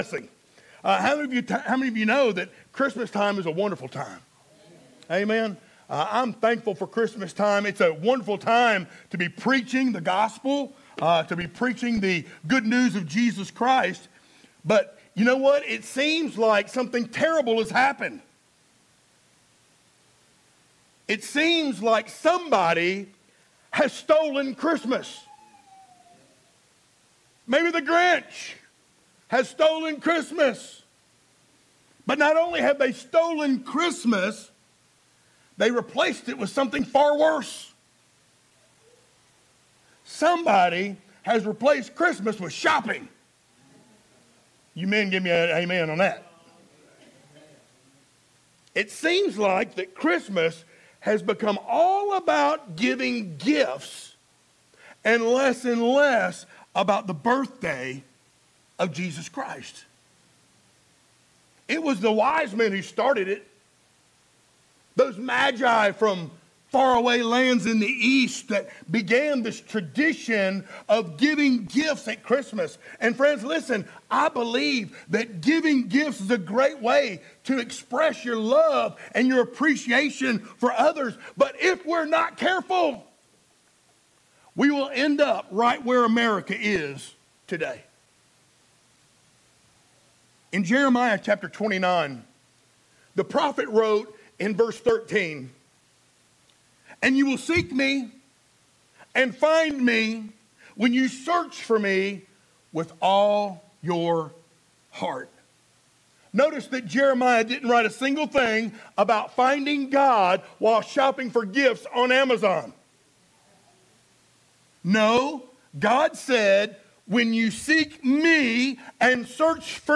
sermons Passage: Matthew 2:1-12 Service Type: Sunday Morning Download Files Notes Topics